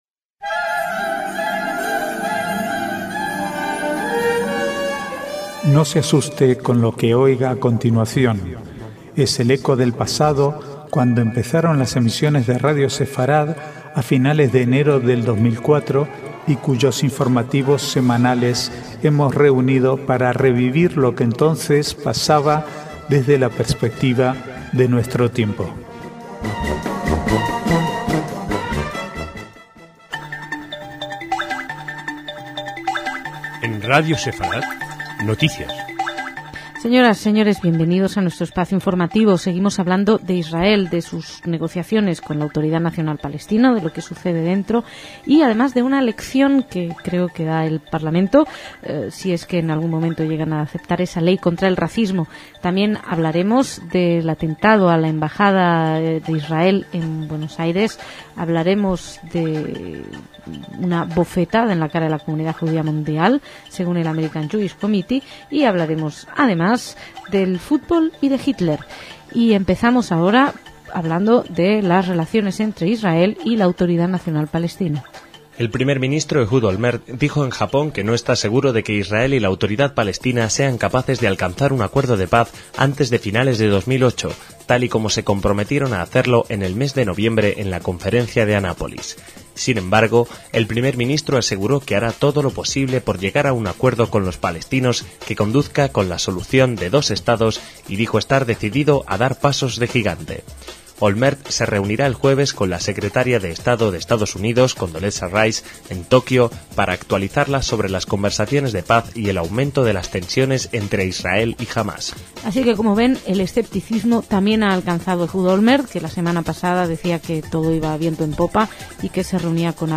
Archivo de noticias del 27 al 29/2/2008